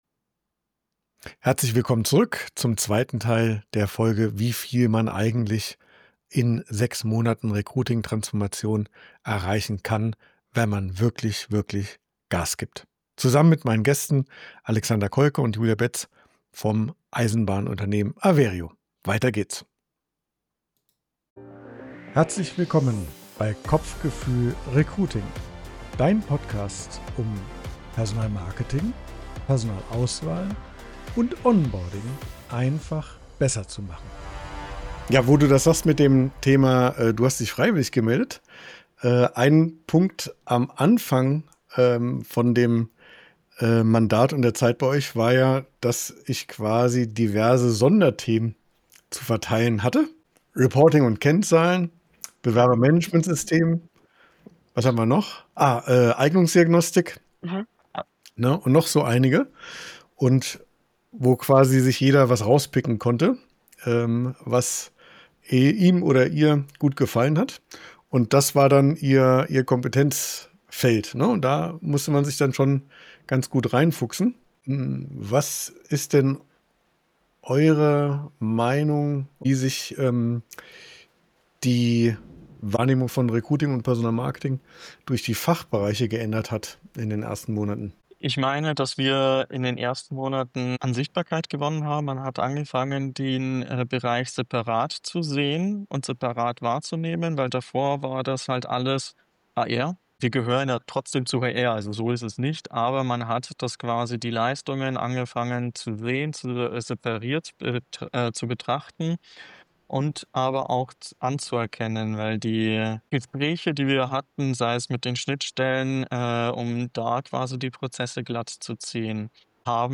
Nr. 28: Kundeninterview: In nur 6 Monaten vom Recruiting-Underdog zum Champion (Part 2) ~ Kopfgefühl Recruiting - 1x1 für Personalmarketing, Personalauswahl & Onboarding Podcast